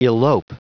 Prononciation du mot elope en anglais (fichier audio)
Prononciation du mot : elope